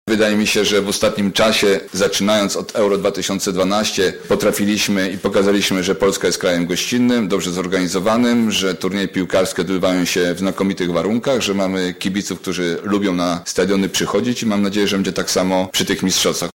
– Organizacja młodzieżowego mundialu nie jest dla nas niespodzianką – mówi Zbigniew Boniek, prezes Polskiego Związku Piłki Nożnej: